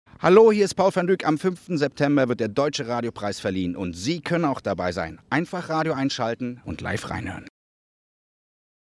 IDs von Paul van Dyk